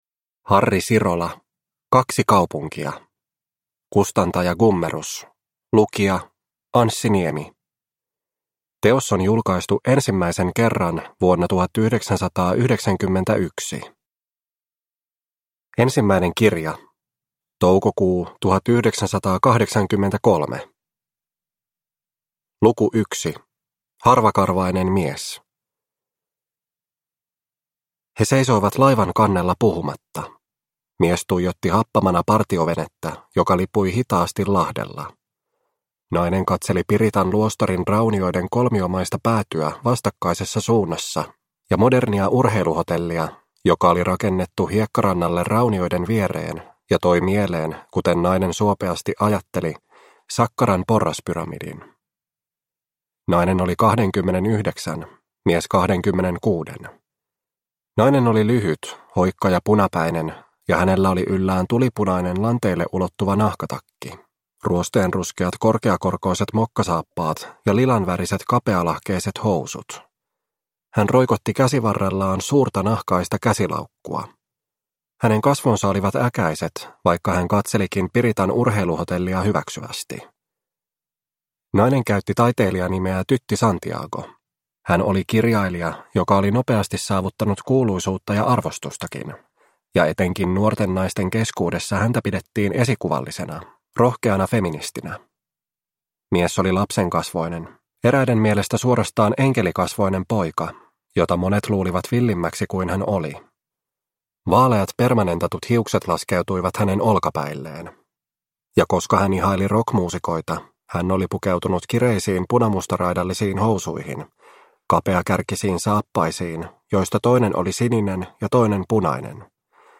Kaksi kaupunkia – Ljudbok – Laddas ner